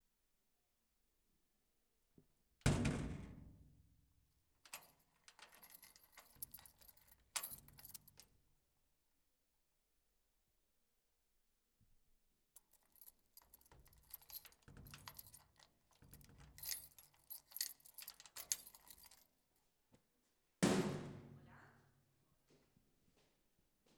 Apertura y cierre de una puerta metálica
Ruido generado por la apertura y cierre de una puerta metálica.